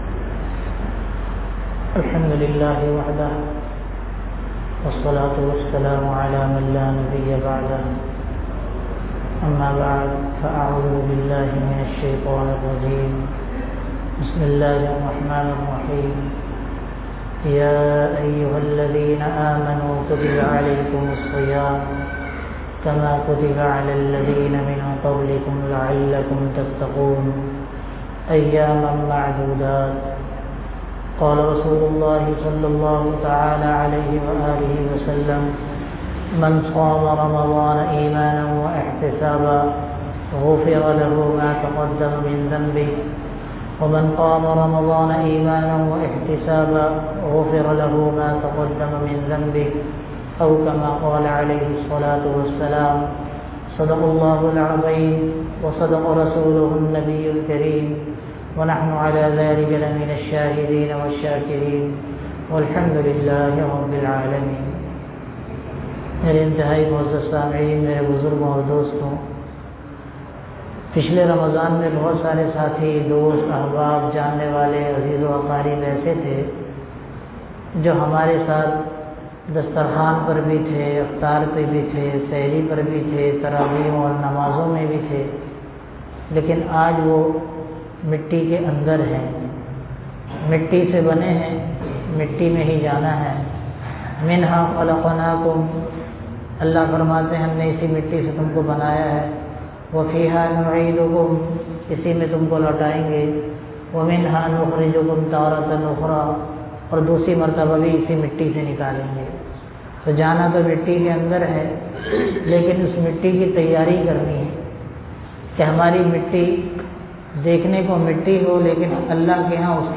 Pulsirat ki 7 check posten (jama masjid abu bakar siddiq ra bad namaz e zuhar orangitown
Bayanat